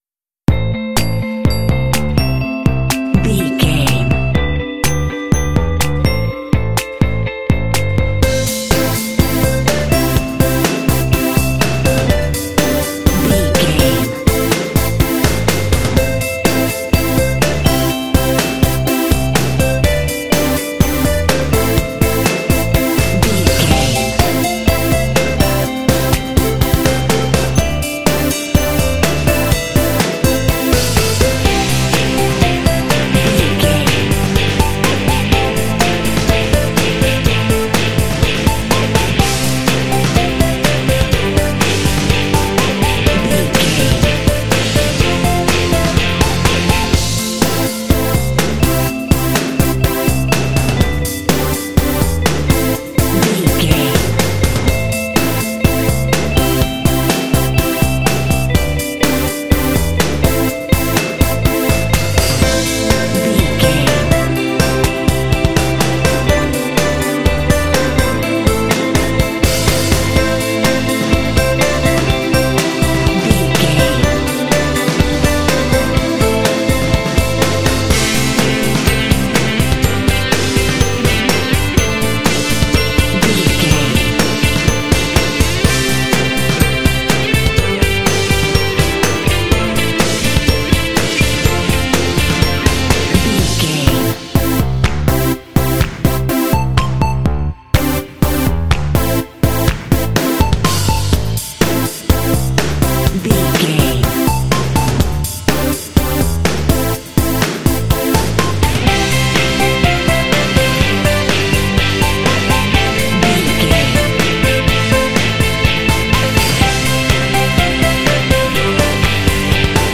Uplifting
Aeolian/Minor
driving
smooth
bass guitar
drums
synthesiser
electric guitar